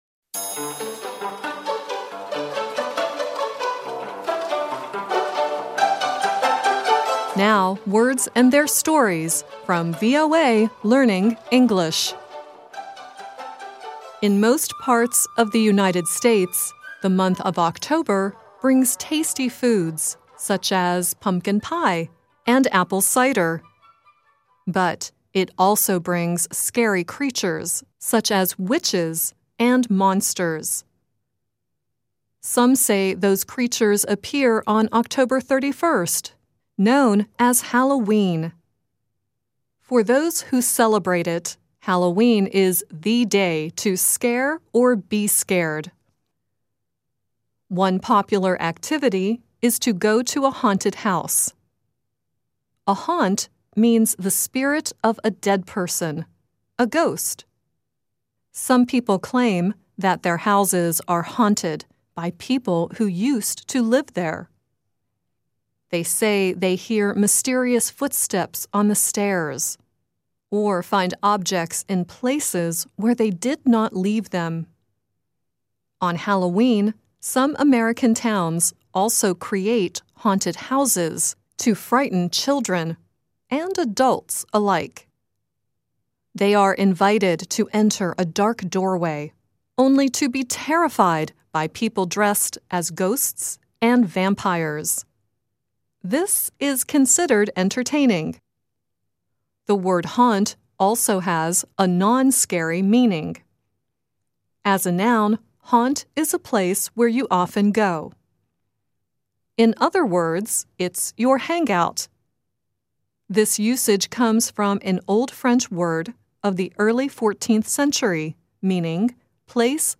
At the end of the program, Sade sings "Haunt Me" at the end.